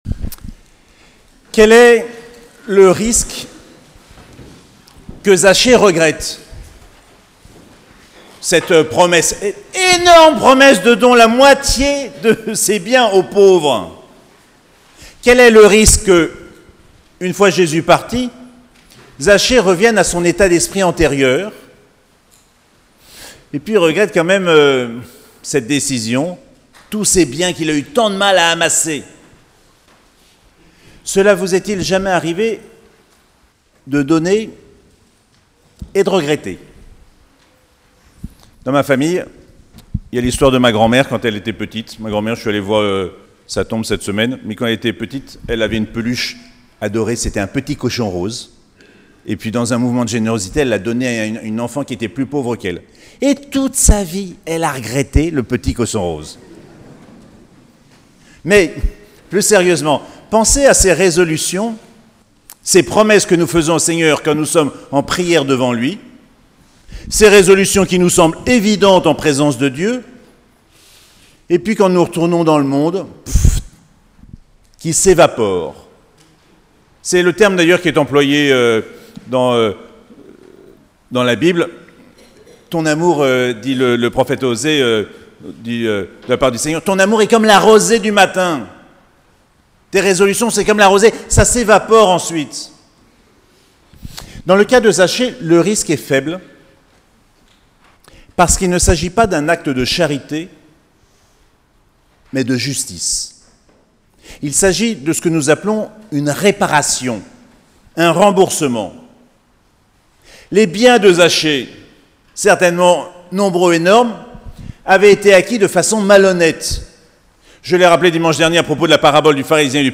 31ème dimanche du Temps Ordinaire - 3 novembre 2019